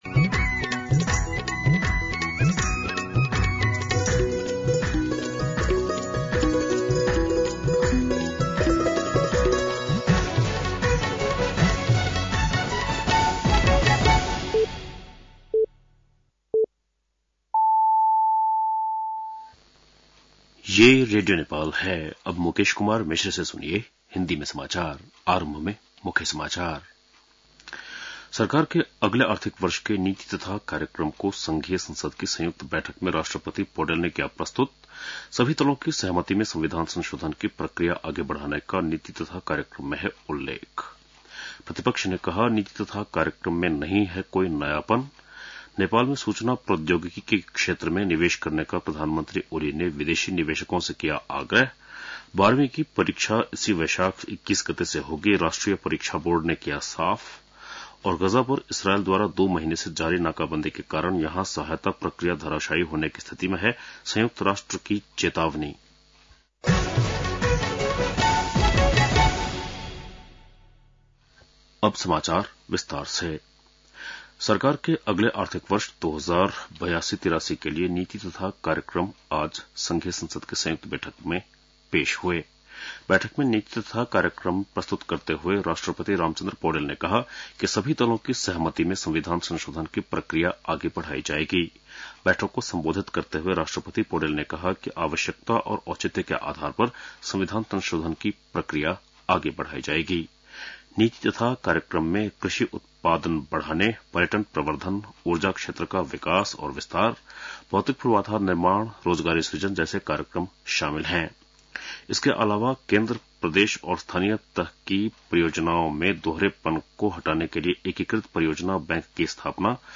बेलुकी १० बजेको हिन्दी समाचार : १९ वैशाख , २०८२